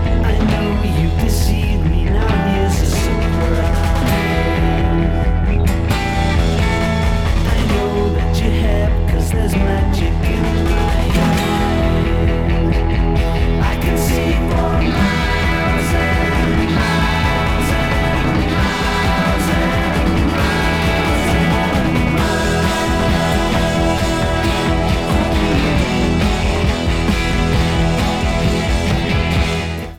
This is a unique mix version for this album